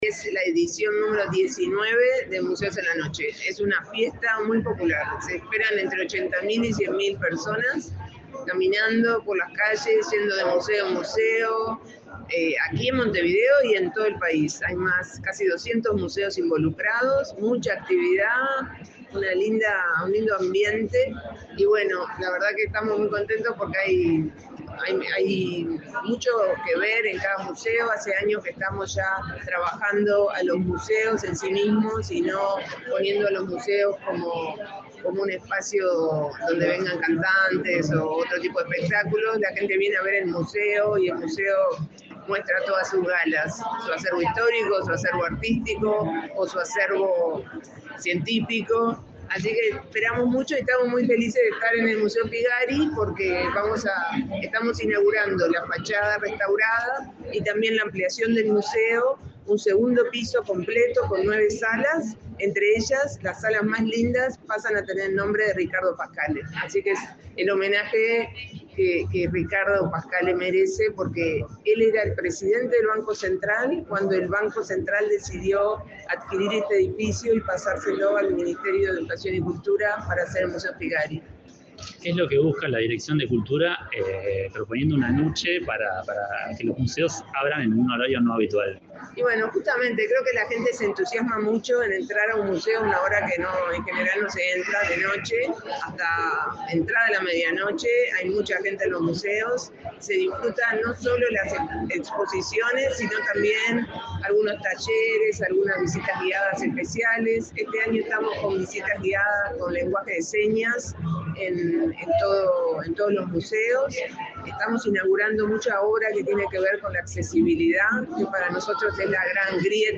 Entrevista a la directora de Cultura del MEC, Mariana Wainstein